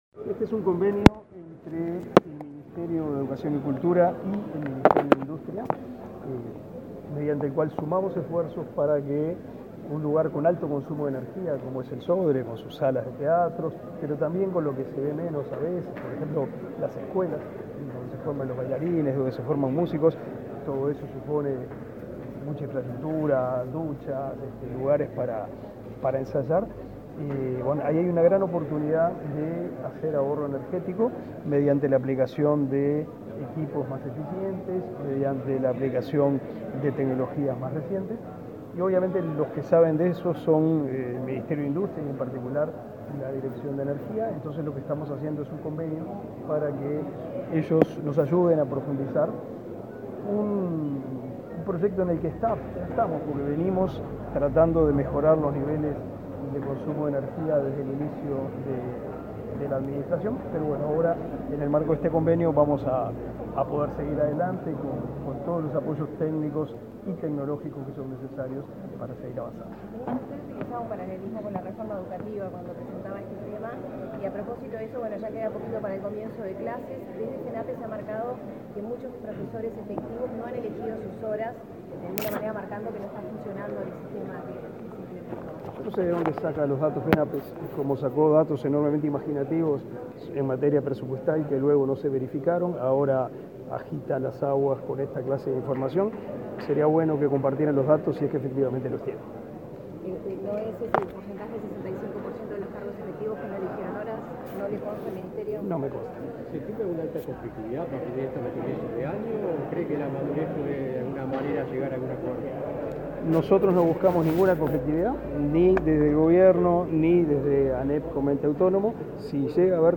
Declaraciones del ministro de Educación y Cultura, Pablo da Silveira
Declaraciones del ministro de Educación y Cultura, Pablo da Silveira 31/01/2023 Compartir Facebook X Copiar enlace WhatsApp LinkedIn El Sodre y la Dirección Nacional de Energía suscribieron un convenio a través del Fideicomiso Uruguayo de Ahorro y Eficiencia Energética, con el objetivo de estimular el uso eficiente de la energía y la movilidad eléctrica. Luego el ministro de Educación y Cultura, Pablo da Silveira, dialogó con la prensa.